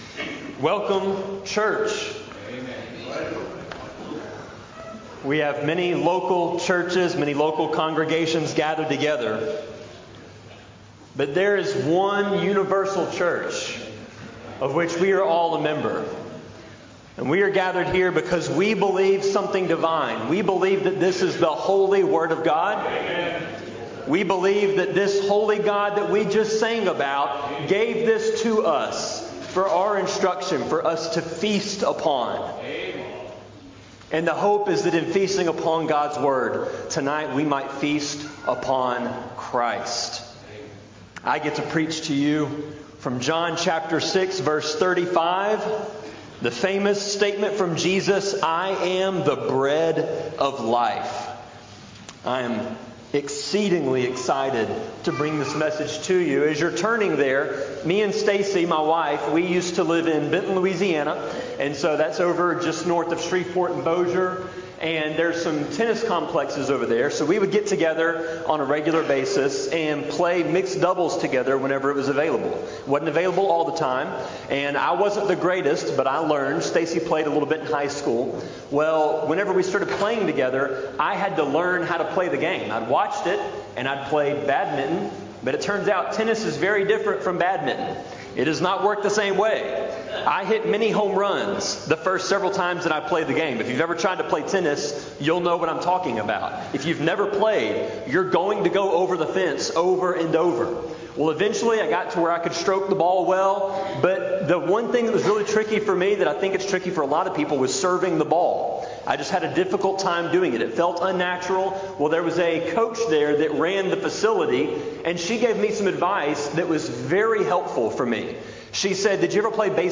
The first out of 8 total sermons in our 2025 joint service series on the